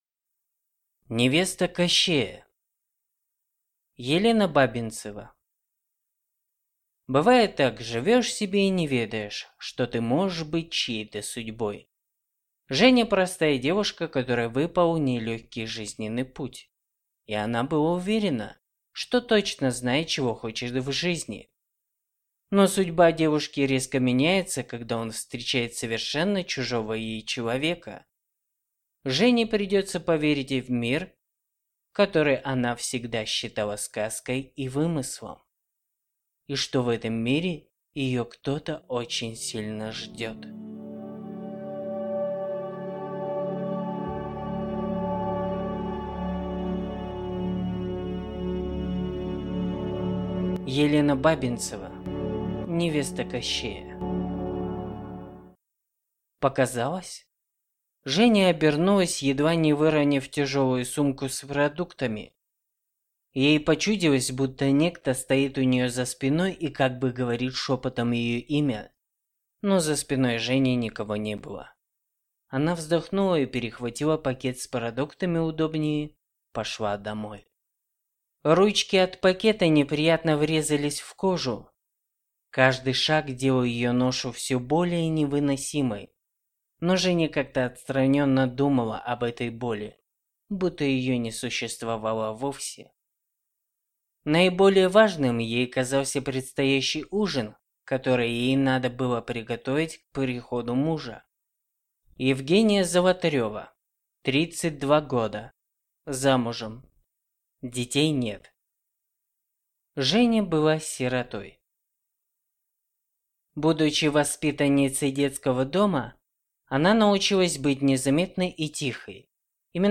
Аудиокнига Невеста Кощея | Библиотека аудиокниг